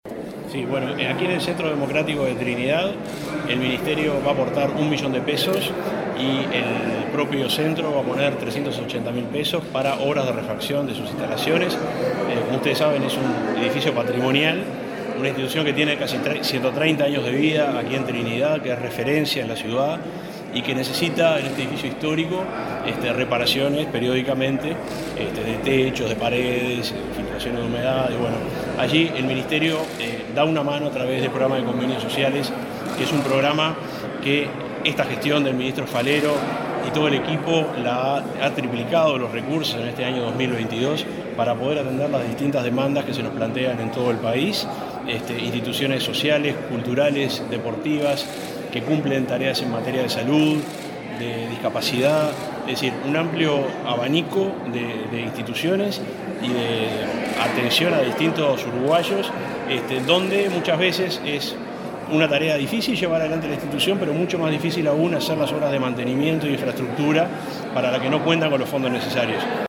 Declaraciones del subsecretario de Transporte
El subsecretario de Transporte, Juan José Olaizola, dialogó con la prensa luego de participar, en el departamento de Flores, en la firma de un